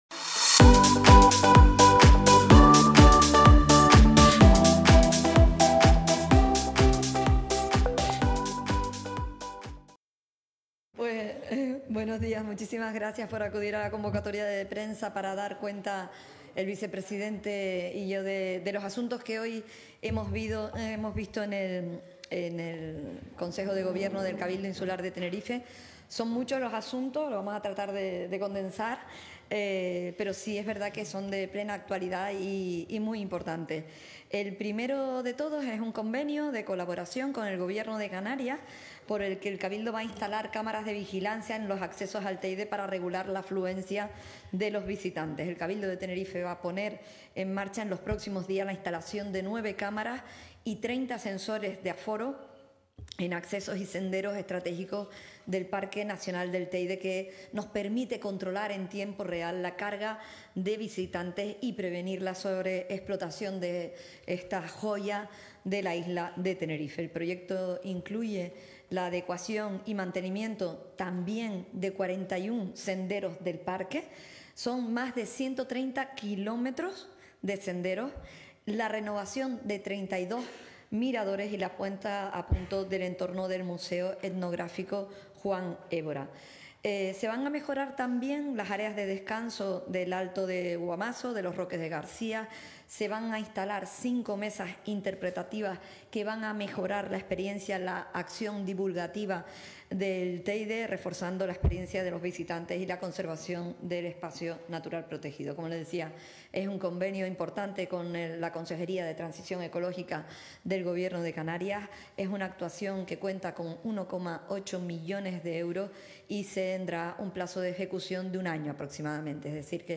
Este miércoles 7 de mayo, a partir de las 11.00 horas, emisión en directo de la rueda de prensa de presentación de los acuerdos del Consejo de Gobierno.